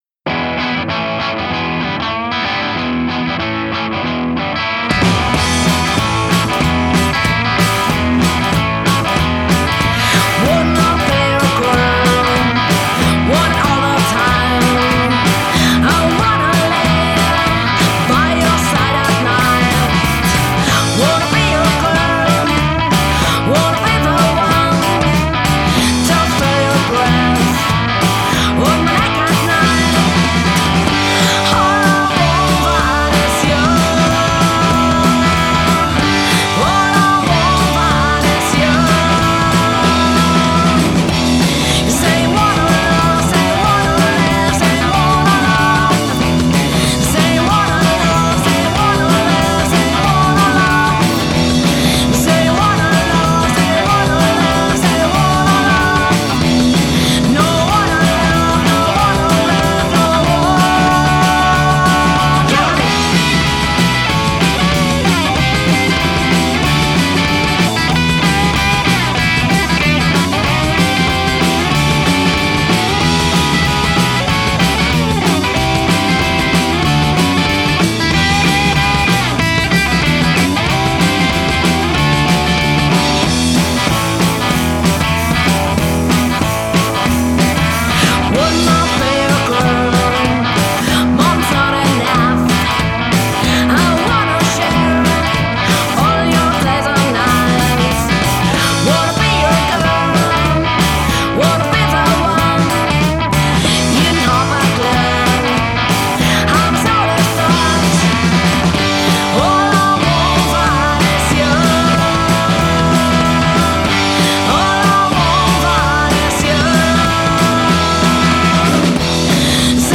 su buen soul, su buen rock & roll
voz principal
guitarra y voz
bajo y voz
batería